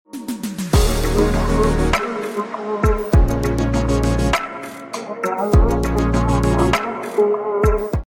Witness the magical birth of a crystal flower. Each shimmer and tone captures the delicate growth of something ancient and powerful. This short video blends visual calm with a rising mystical melody — a moment...